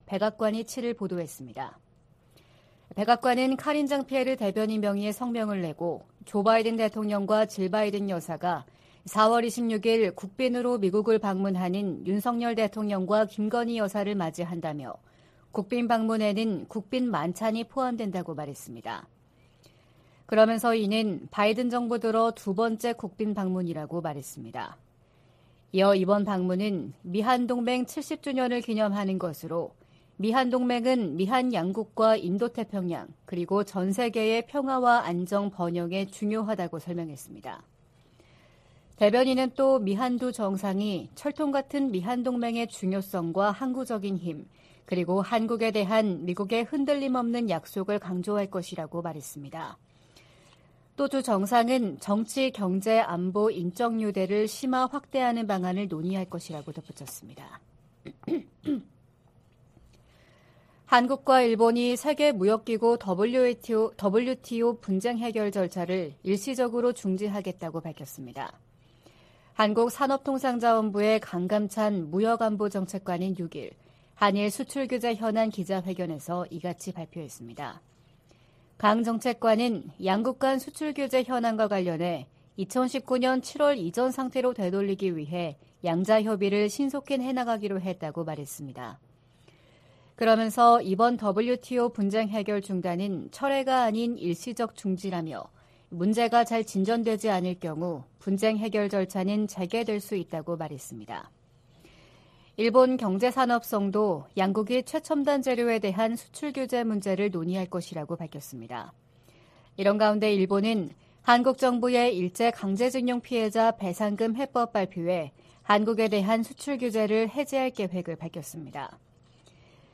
VOA 한국어 '출발 뉴스 쇼', 2023년 3월 8일 방송입니다. 북한이 이달 또는 다음달 신형 고체 대륙간탄도미사일(ICBM)이나 정찰위성을 발사할 가능성이 있다고 한국 국가정보원이 전망했습니다. 미 국무부는 강제징용 문제 해법에 대한 한일 간 합의를 환영한다는 입장을 밝혔습니다. 한국이 역사 문제 해법을 발표한 데 대해 일본도 수출규제 해제 등으로 적극 화답해야 한다고 미국 전문가들이 주문했습니다.